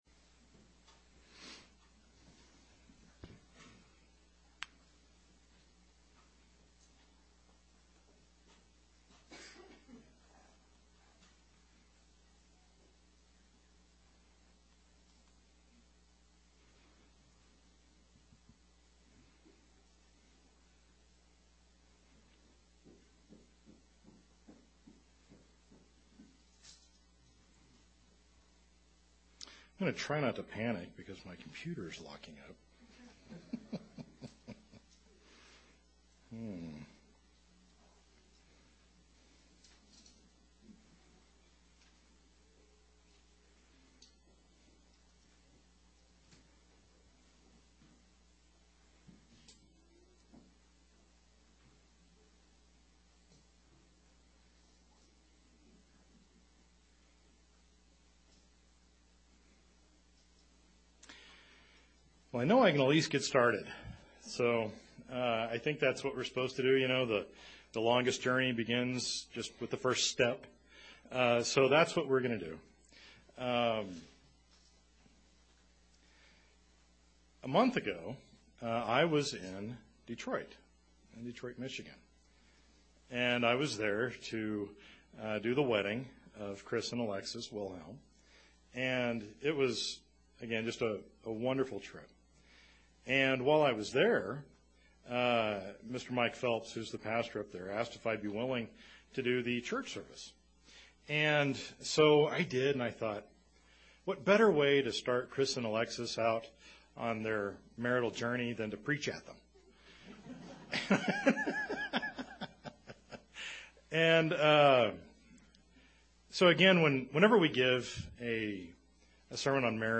In this sermon we will see that just as the heart of a woman needs the love of her husband, so is respect the key to a man's heart.
Given in Colorado Springs, CO Denver, CO Loveland, CO